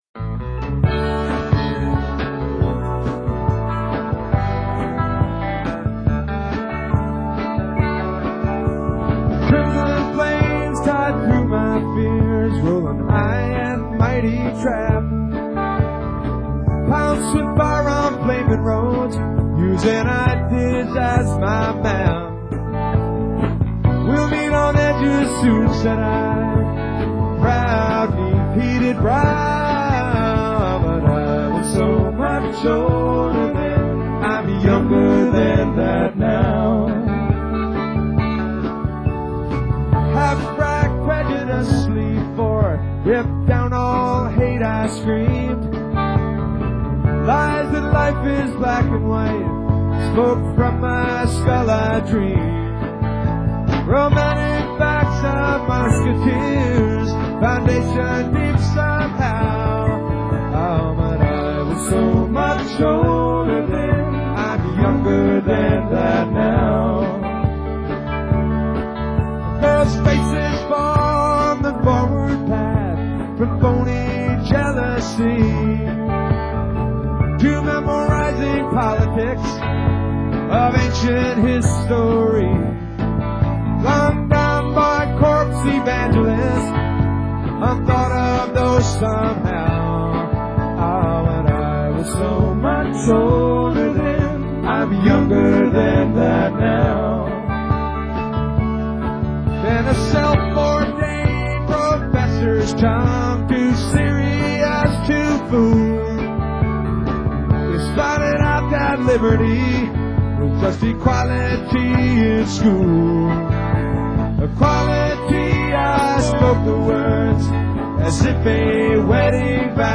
keyboards & vocals
drums
bass & vocals
guitar & vocals